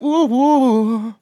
Categories: Vocals Tags: dry, english, fill, LOFI VIBES, male, OHOHOH, sample
MAN-LYRICS-FILLS-120bpm-Am-3.wav